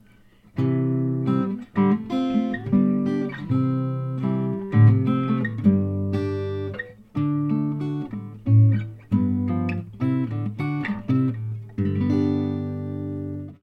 This time I got better sound, louder, with wider frequency range and less noise:
Second aluminum leaf sample